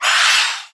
naga_mage_damage.wav